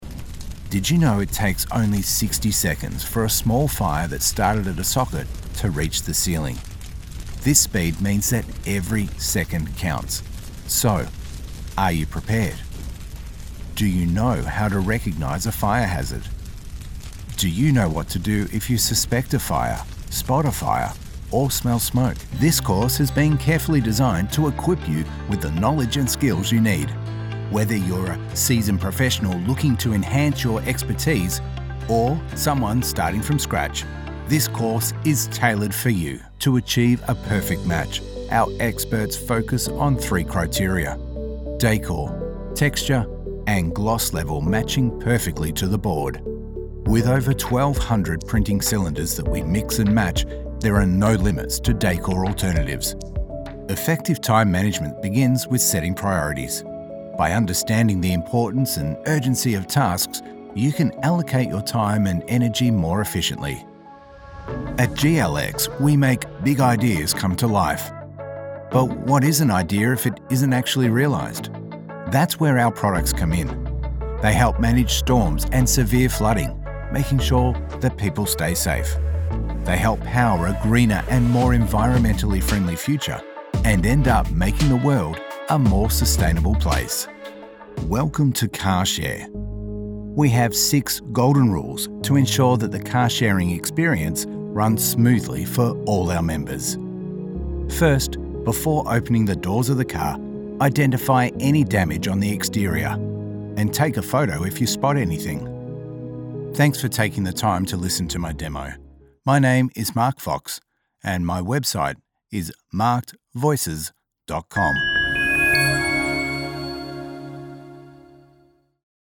Male
English (Australian)
Casual, professional or announcer-ry, he brings every script to life.
0318eLearning.mp3